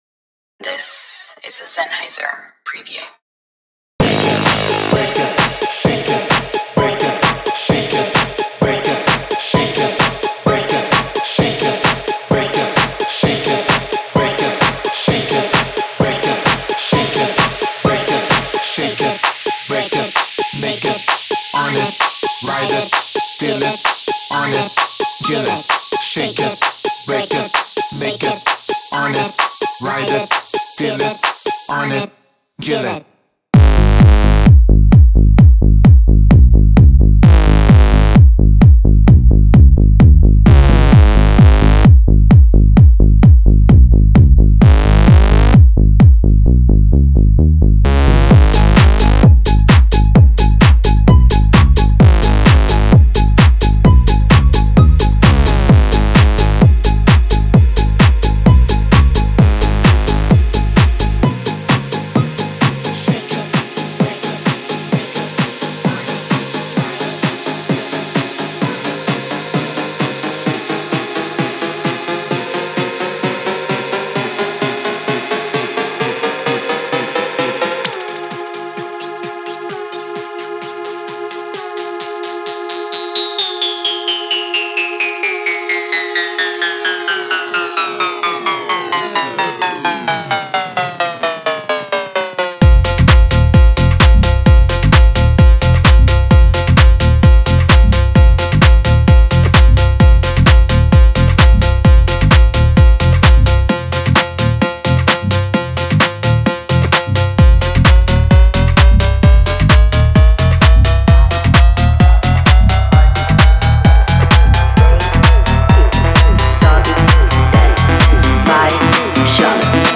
Genre:Tech House
シンセサウンドは荒々しく、ボーカルラインは刺激的、音楽ループには自信たっぷりのスワッガーが満ちています。
デモサウンドはコチラ↓